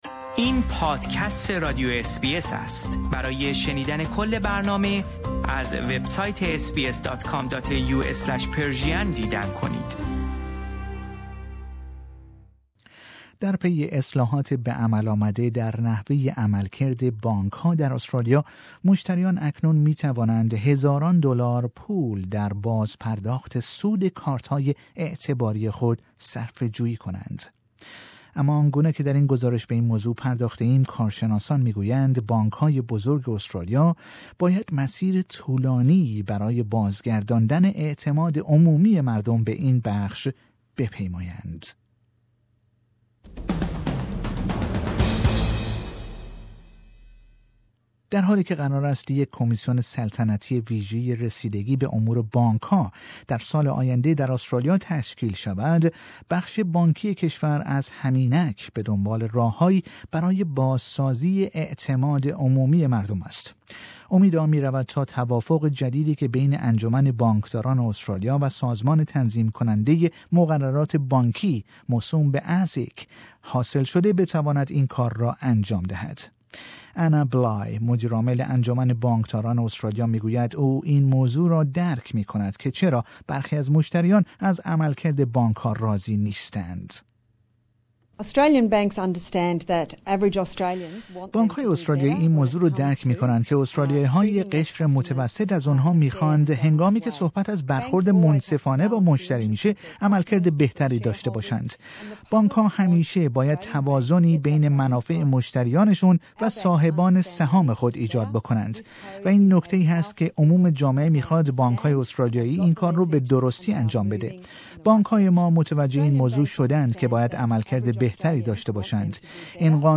در پی اصلاحات به عمل آمده در نحوه عملکرد بانک ها در استرالیا، مشتریان اکنون می توانند هزاران دلار پول در بازپرداخت سود کارت های اعتباری خود صرفه جویی کنند. اما آنگونه که در این گزارش به این موضوع پرداخته ایم کارشناسان می گویند بانک های بزرگ استرالیا باید مسیر طولانی برای بازگرداندن اعتماد عمومی مردم به این بخش بپیمایند.